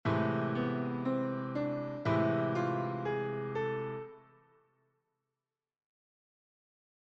Gamme Mineure Harmonique
• En LA :
mineur_harmonique_la.mp3